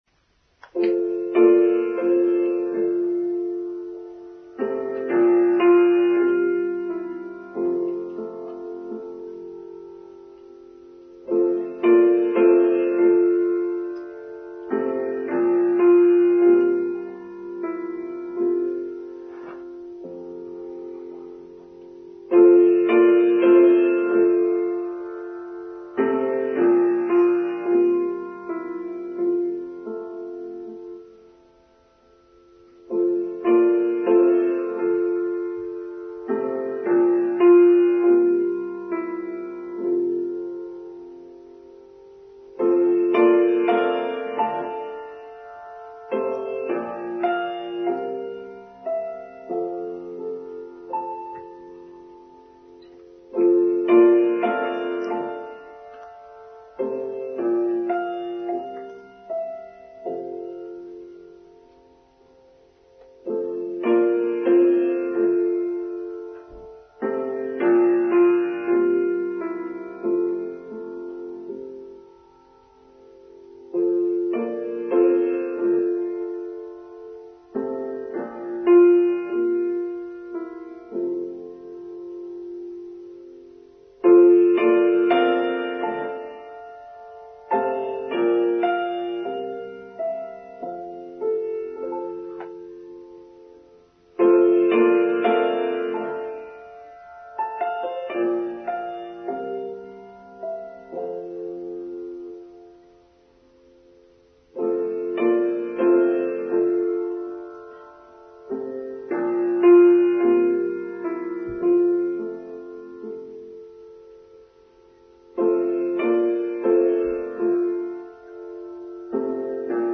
Discipline: Online Service for Sunday 5th February 2023